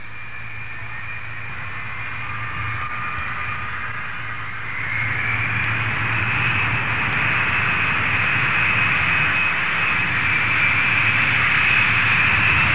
دانلود آهنگ طیاره 18 از افکت صوتی حمل و نقل
دانلود صدای طیاره 18 از ساعد نیوز با لینک مستقیم و کیفیت بالا
جلوه های صوتی